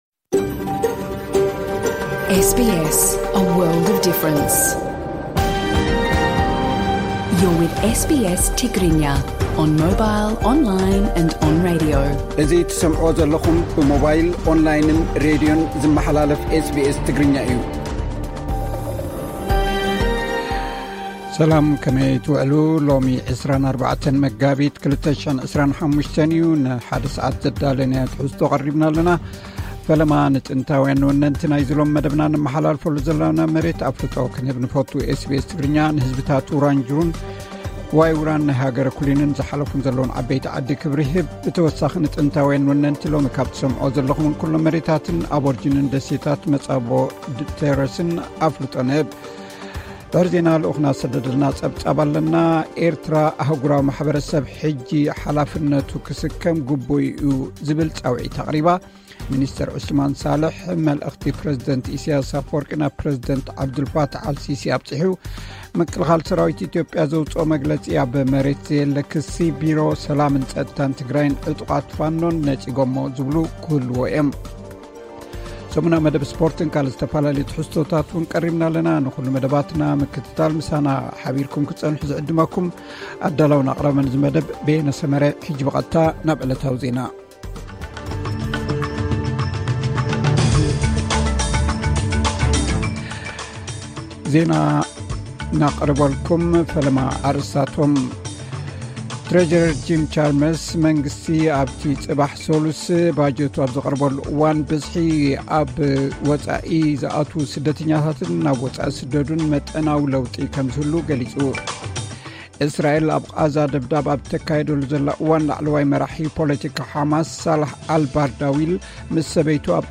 ዕለታዊ ዜና ኤስ ቢ ኤስ ትግርኛ (24 መጋቢት 2025)